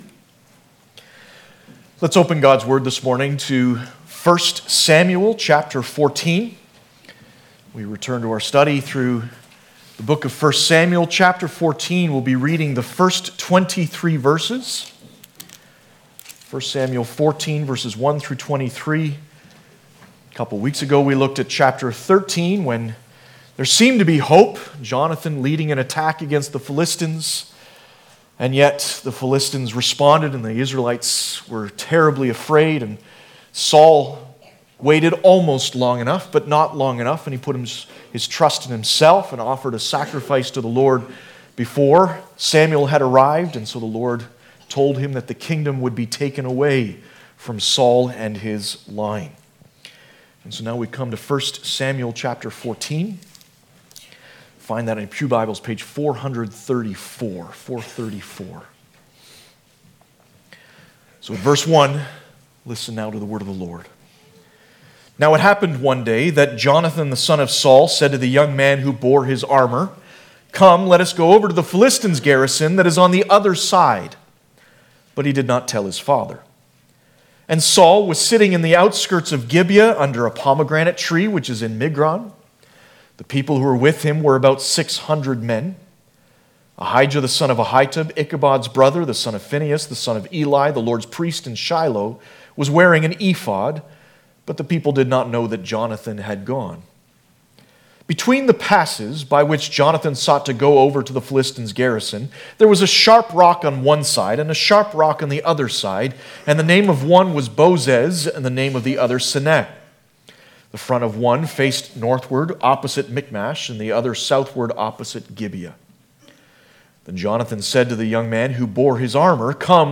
1 Samuel Passage: 1 Samuel 14:1-23 Service Type: Sunday Morning Download Files Bulletin « In Search of Disciples Pt. 2 Have mercy upon me